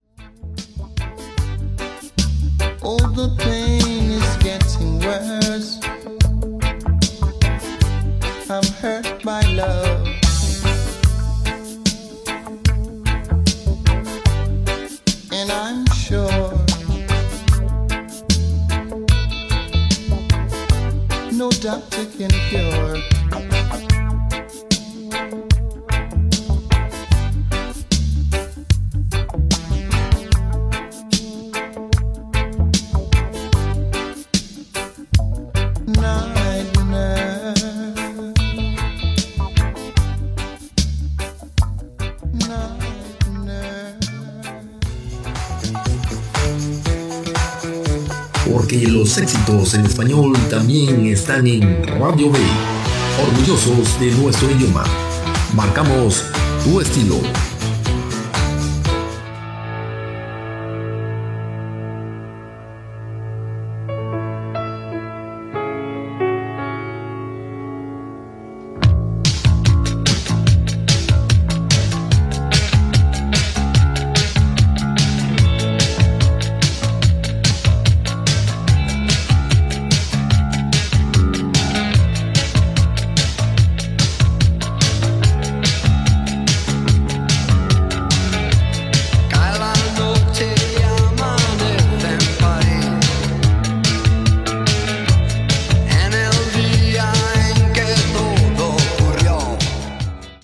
Tema musical, identificació, tema musical.
Musical